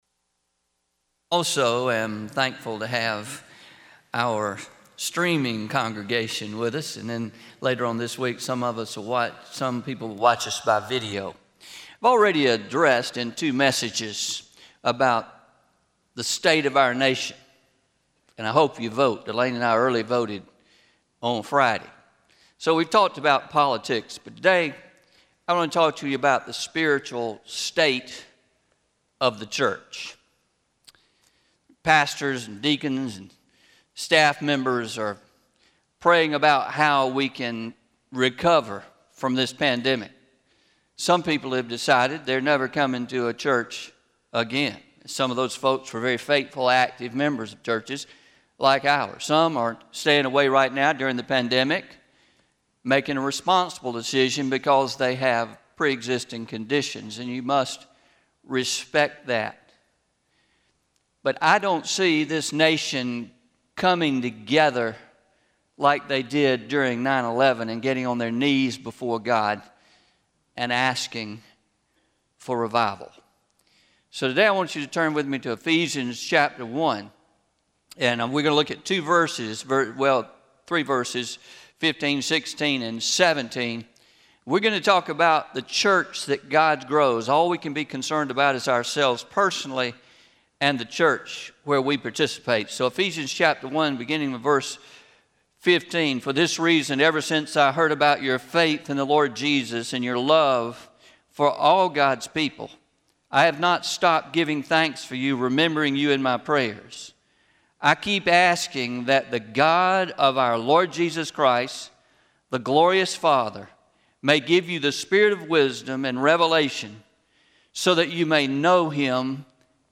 10-25-20am Sermon – The Church God Grows – Traditional